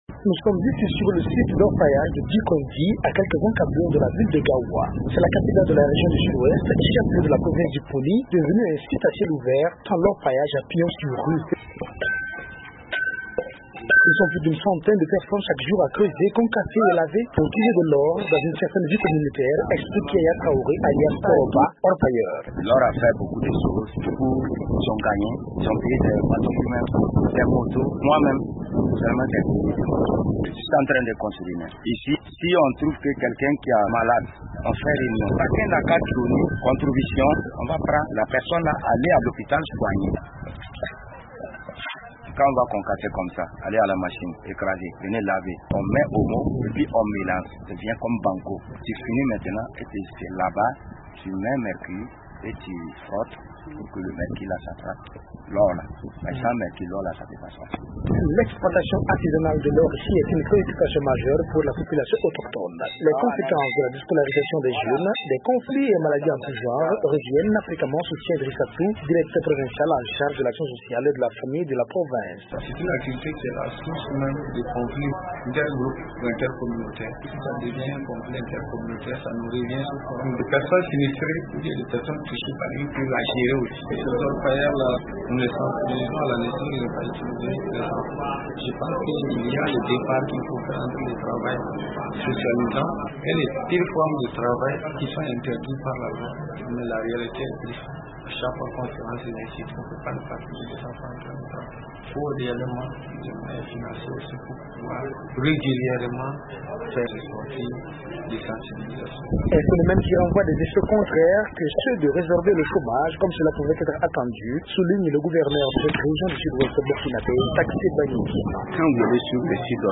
En marge de la commémoration officielle de la fête nationale de l'indépendance du Burkina dans la ville de Gaoua
Reportage